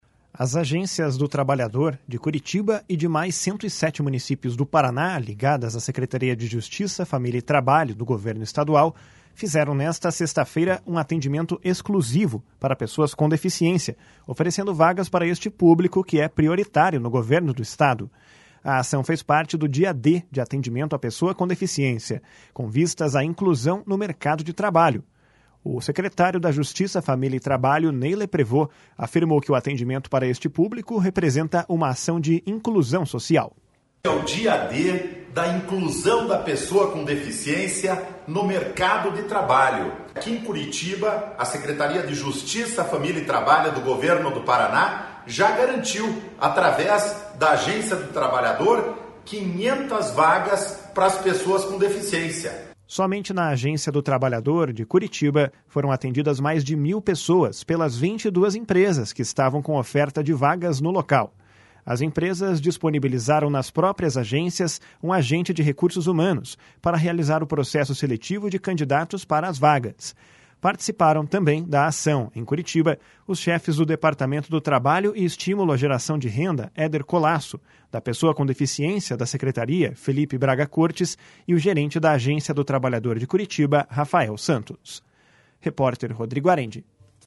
A ação fez parte do Dia D de atendimento à pessoa com deficiência, com vistas à inclusão no mercado de trabalho. O secretário da Justiça, Família e Trabalho, Ney Leprevost, afirmou que o atendimento para este público representa uma ação de inclusão social. // SONORA NEY LEPREVOST //